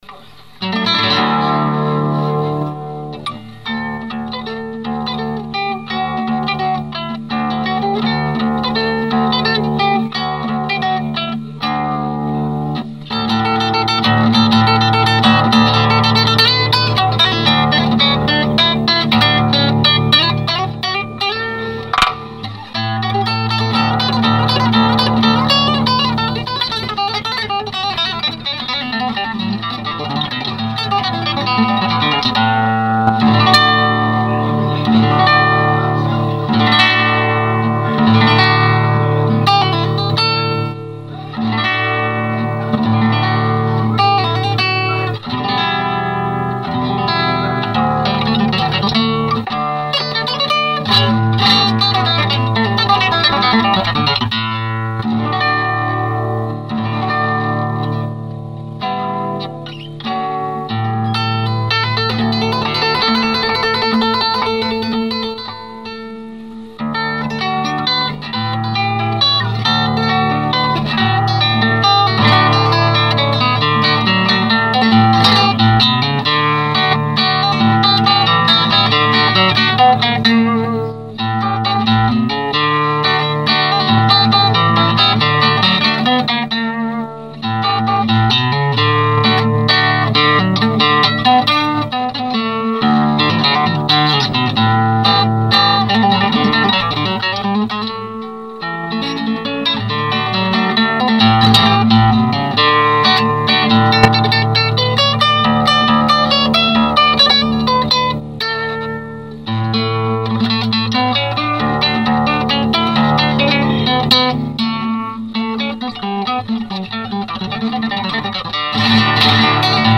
j'ai rate un peu les picado a la fin :confused: